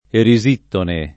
[ eri @& ttone ]